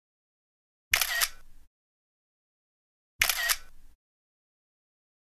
photo click sound effect
photo-click-sound-effect